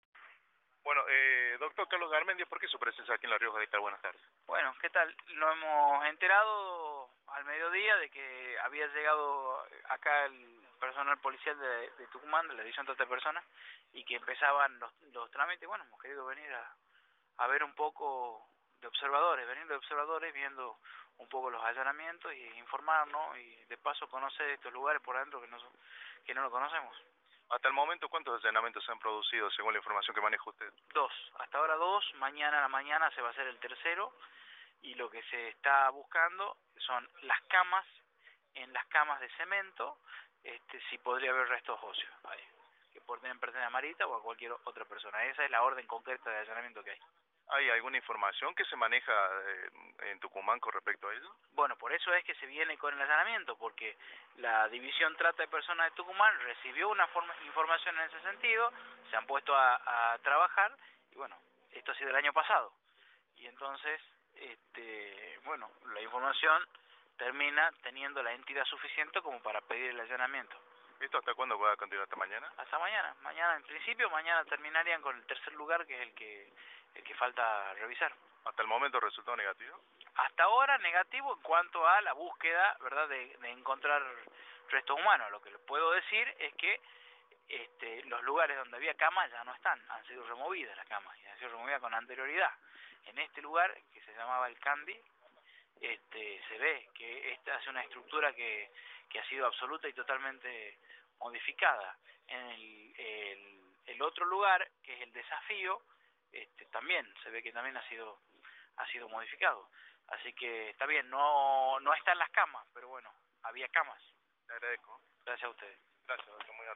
abogado, por Cadena 3 Argentina